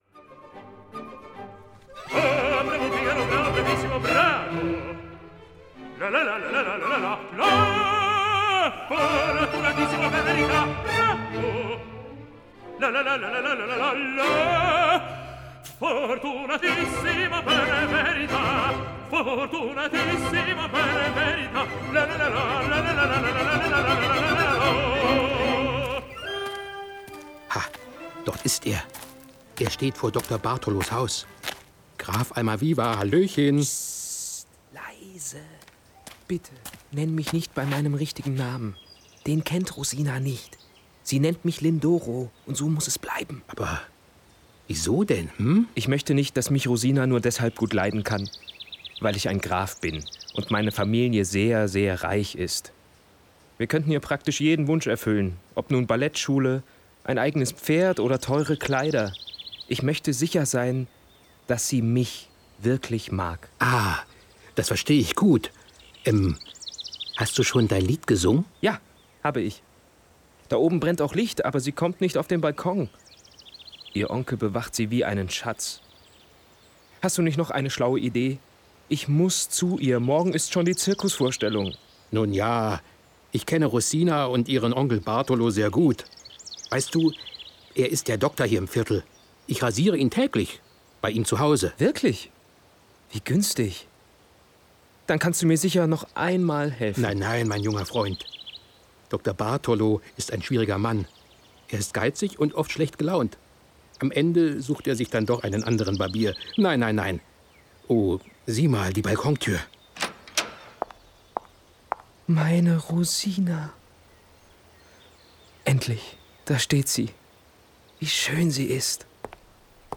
Hörspiel mit Opernmusik